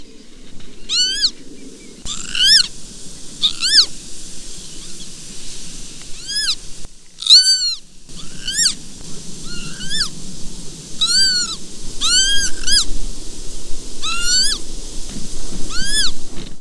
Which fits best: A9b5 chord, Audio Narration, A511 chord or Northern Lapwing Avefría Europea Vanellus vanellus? Northern Lapwing Avefría Europea Vanellus vanellus